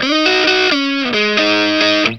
BLUESY3 GS90.wav